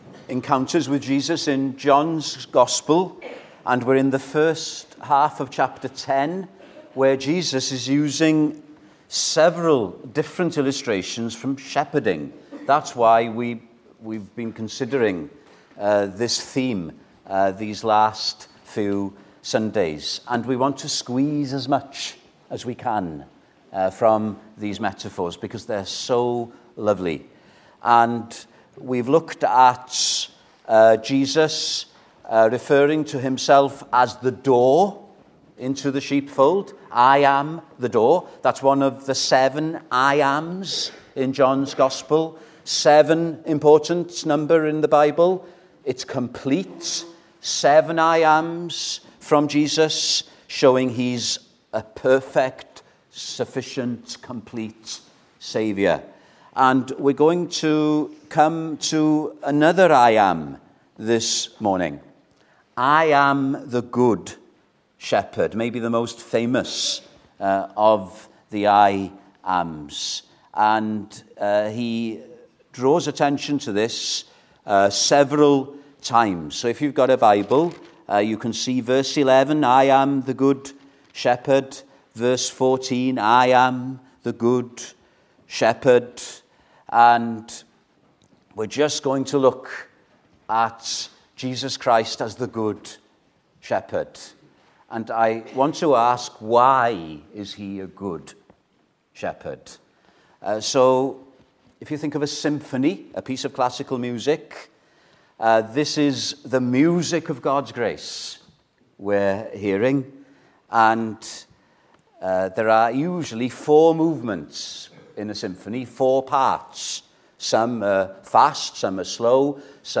12 October 2025, Morning Sermon